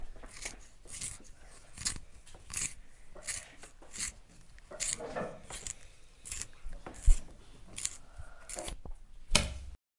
磨刀器
描述：花式铅笔刀。